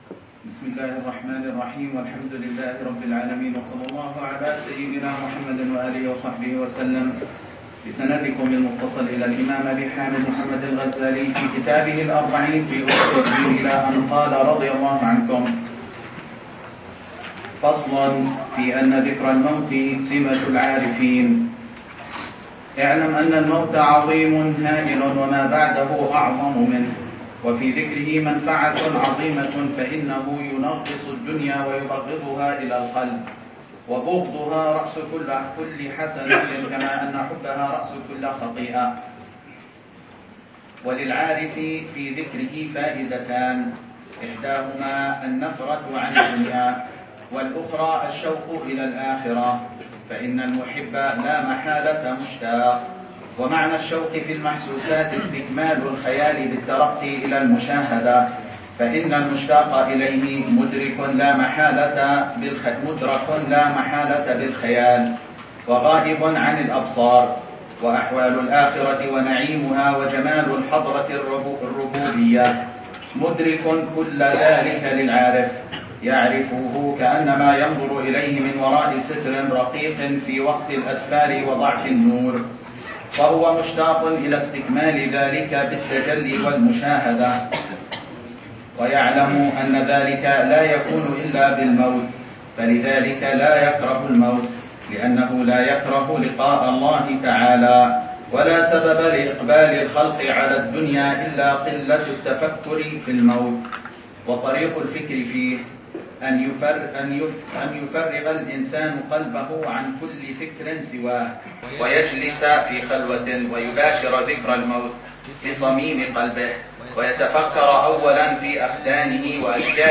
الدرس ال42 في شرح الأربعين في أصول الدين: اذكروا هادم اللذات: تأملات تنقي القلب من طول الأمل، وتفتح طريق الاستعداد للقاء.